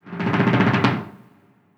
Drum Roll (3).wav